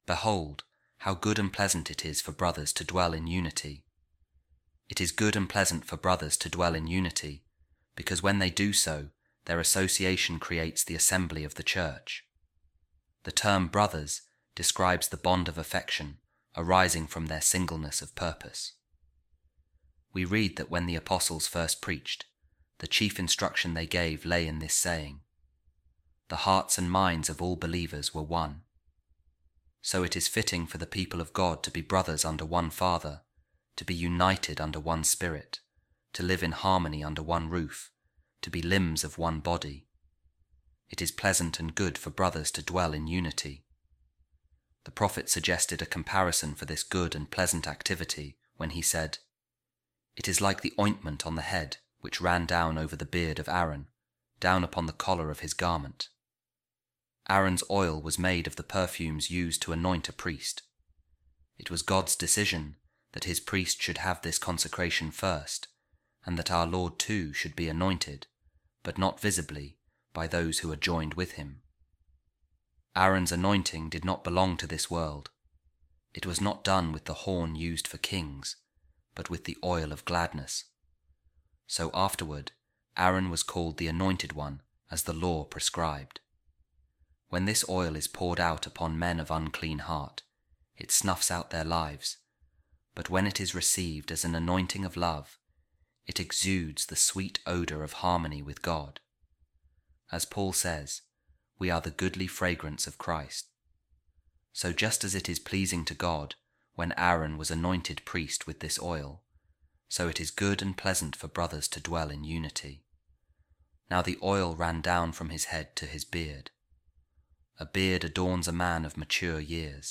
A Reading From The Discourse Of Saint Hilary Of Poitiers On The Psalms | The Company Of Those Who Believed Were Of One Heart And Soul